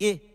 Yeah Vox.wav